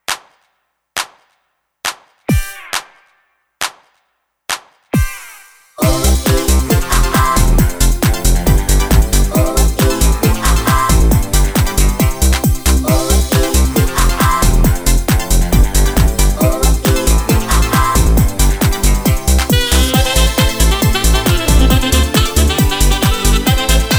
No Doctor Voice Comedy/Novelty 2:54 Buy £1.50